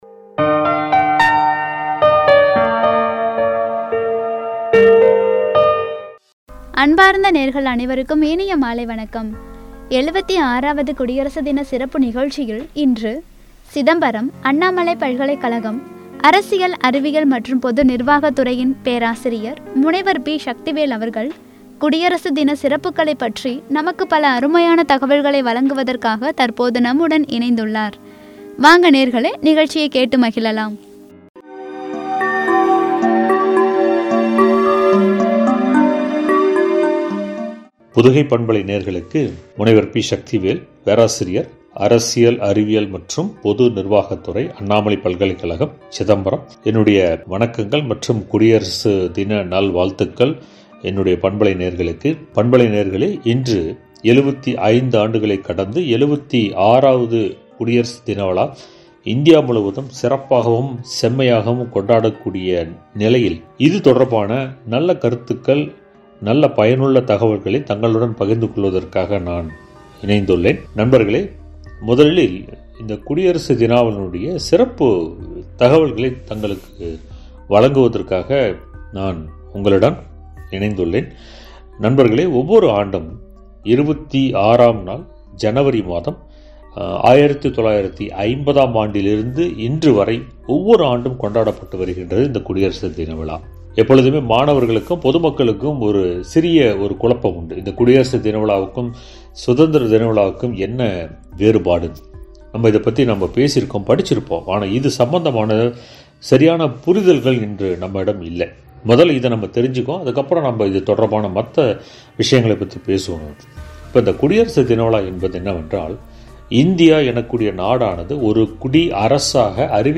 என்ற தலைப்பில் வழங்கிய உரை.